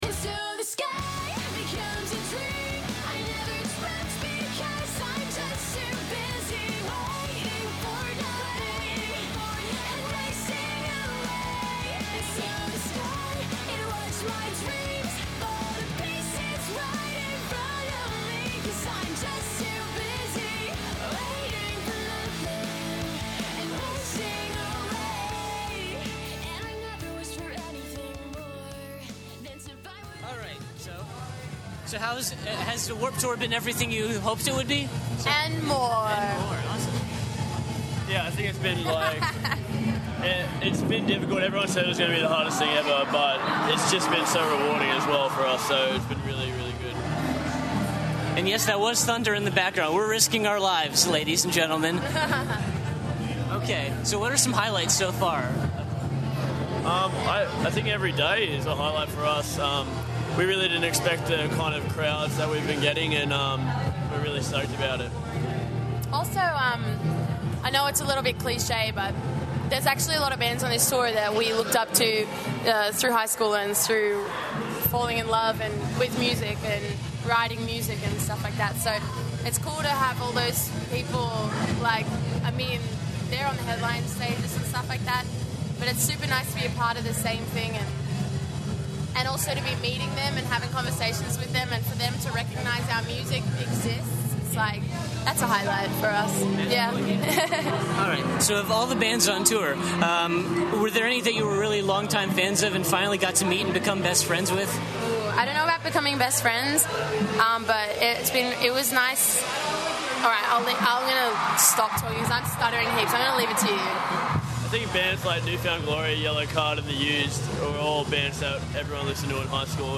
Exclusive: Tonight Alive Interview
21-interview-tonight-alive.mp3